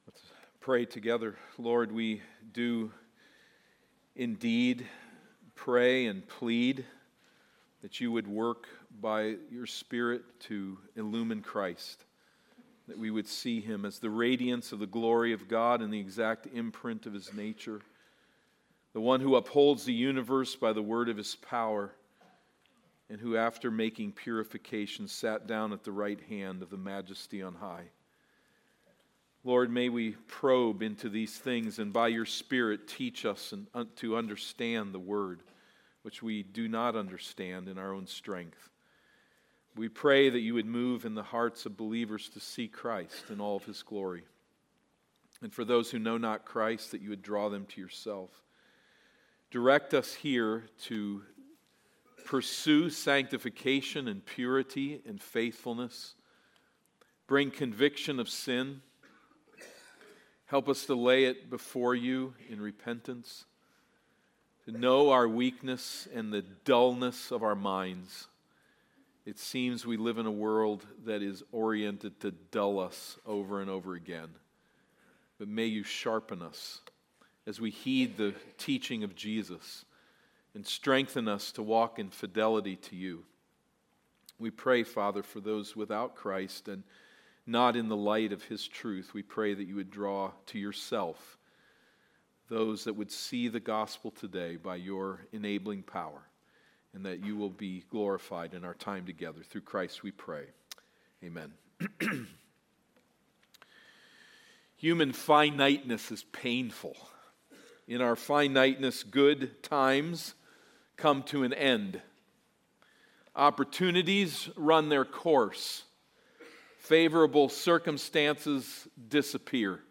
Sermons
Sermons from Eden Baptist Church: Burnsville, MN